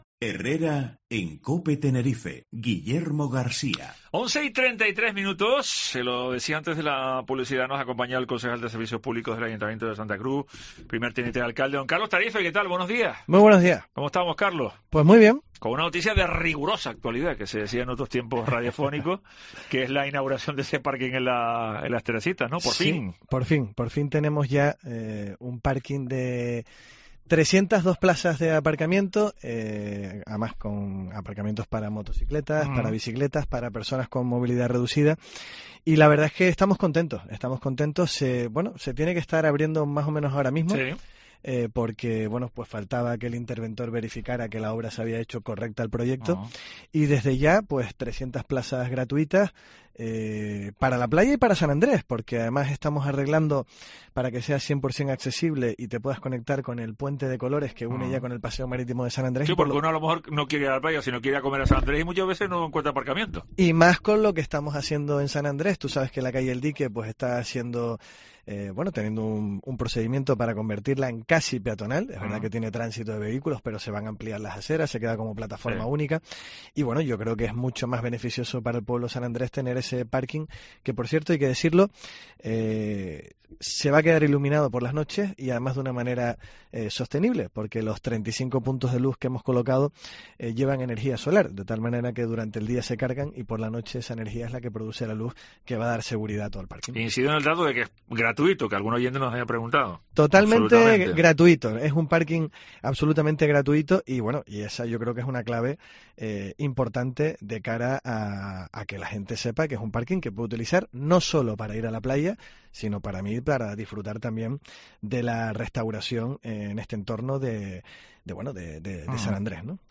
Entrevista Carlos Tarife en Herrera en COPE Tenerife